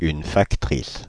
Ääntäminen
France (Paris): IPA: /fak.tʁis/